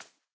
hop1.ogg